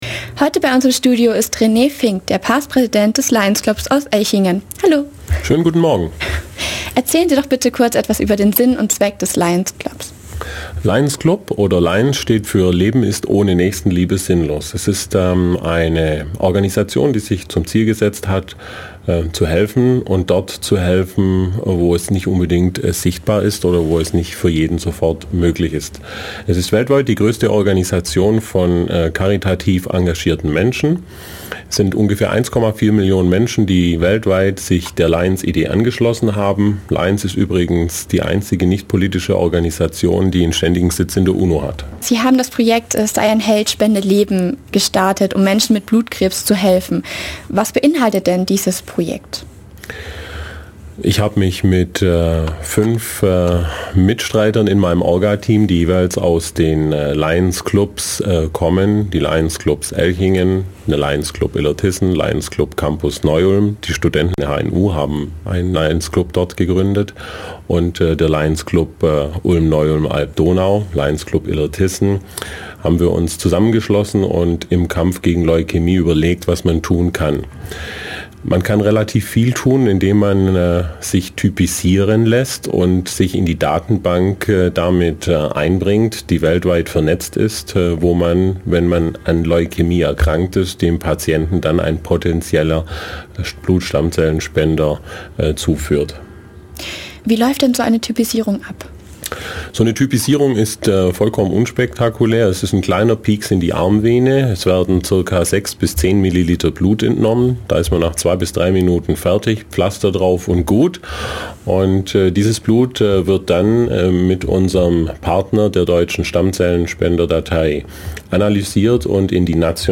interview_typisierungsprojekt.mp3